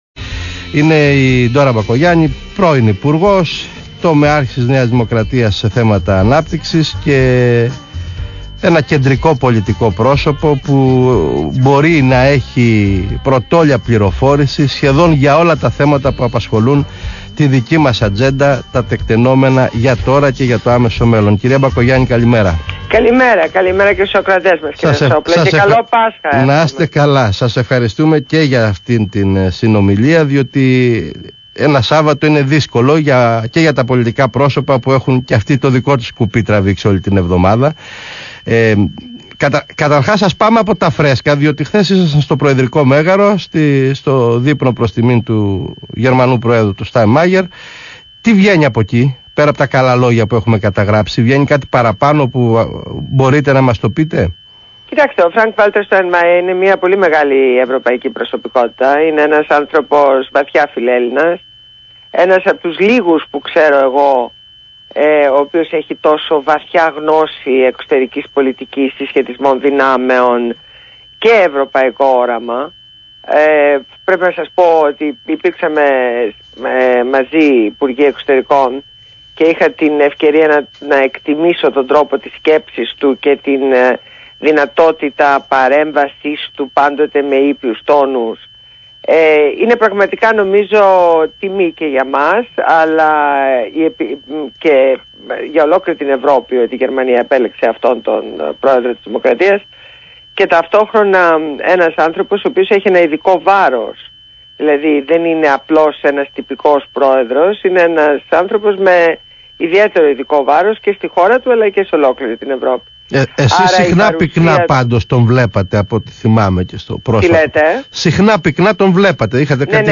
Συνέντευξη στο ραδιόφωνο BHMAfm